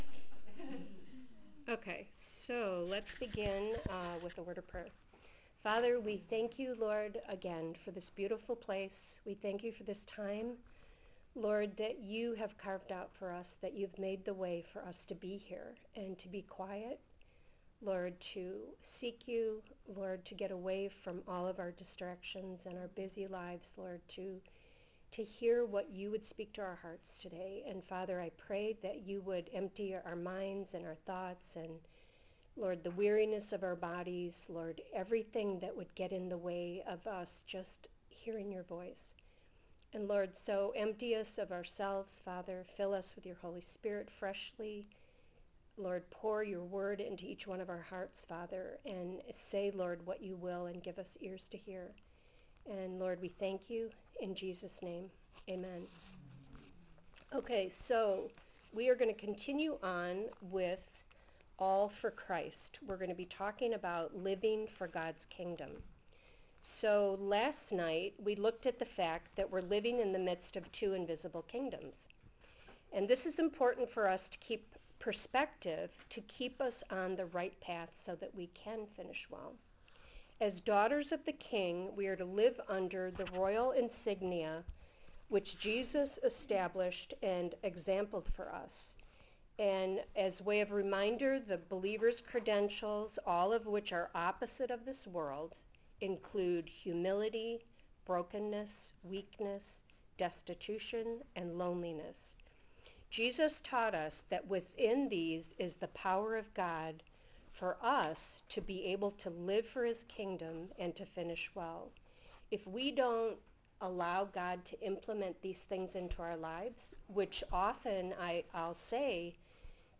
002 - All For Christ (2025 Women’s Conference)